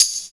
85 TAMB 2.wav